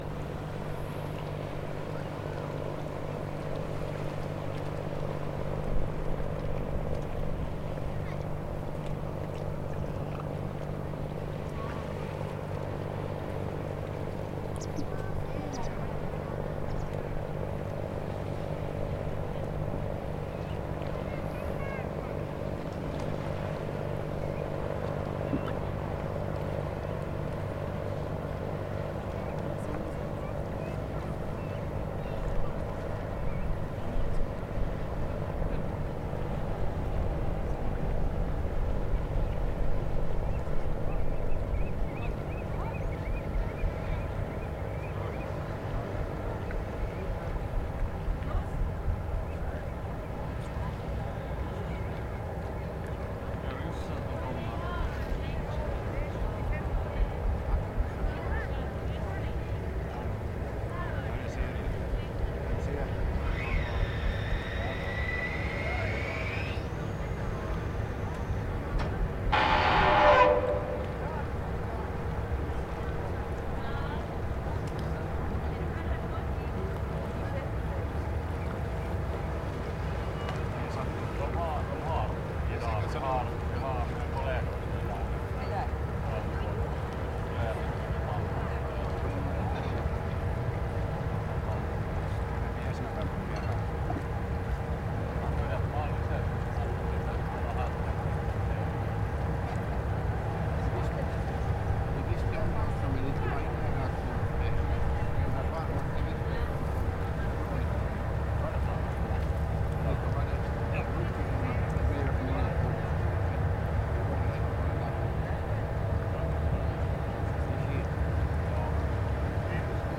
Ferry comes in to dock at Uto
A ferry arrives into dock at the tiny Finnish island of Uto.